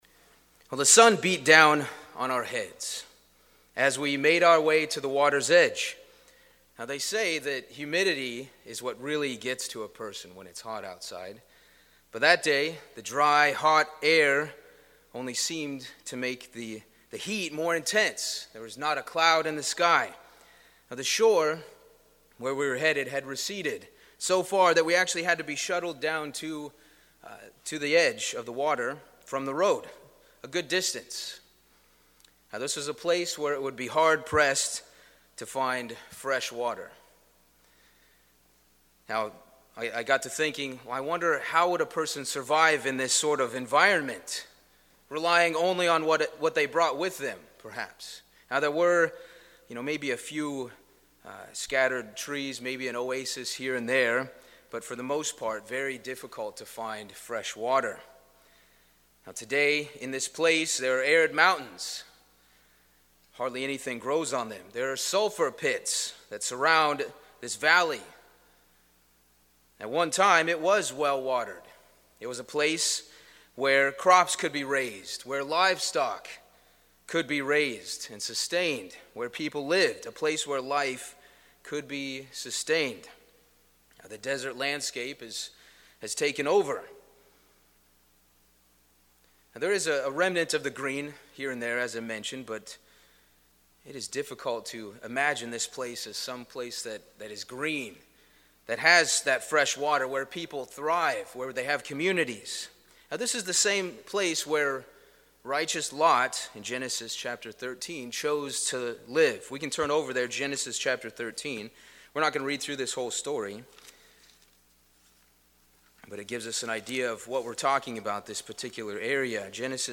This sermon was given at the Ocean City, Maryland 2023 Feast site.